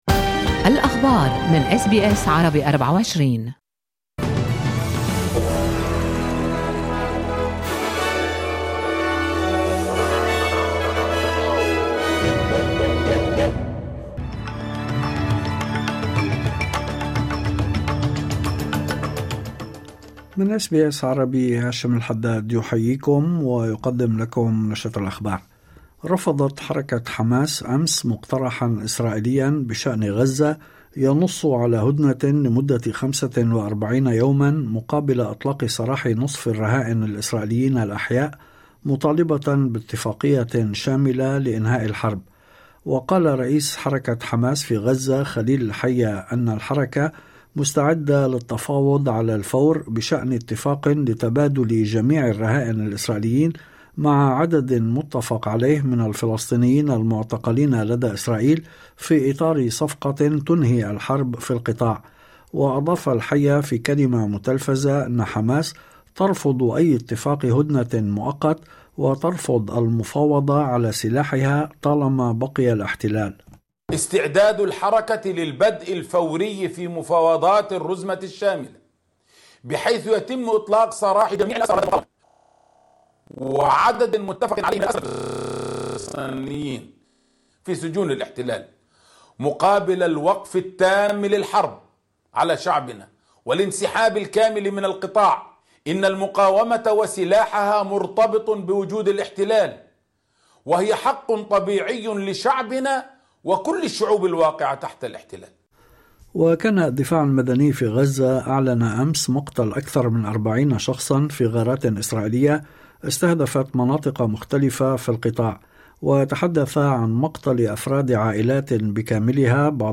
نشرة أخبار الظهيرة 18/04/2025